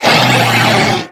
Cri de Lougaroc (Forme Crépusculaire) dans Pokémon Ultra-Soleil et Ultra-Lune.
Cri_0745_Crépusculaire_USUL.ogg